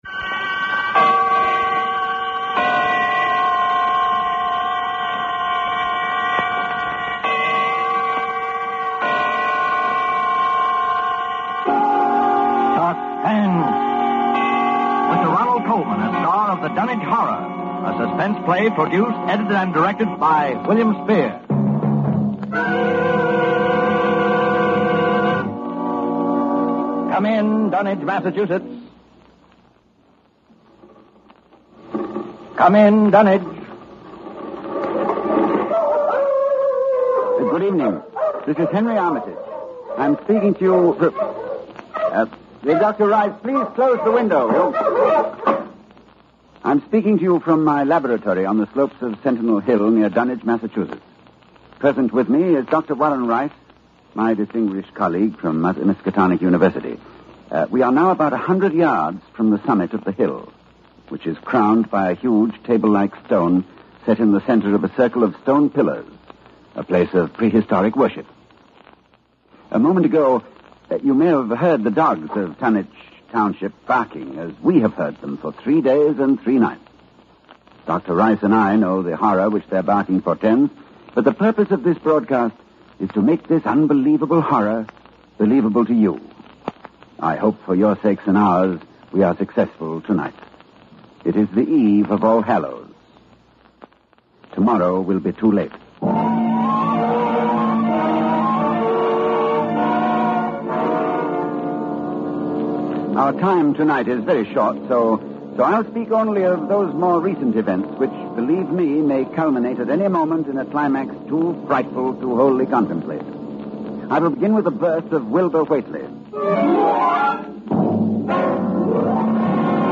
Free Listen: Five Lovecraftian old-time radio shows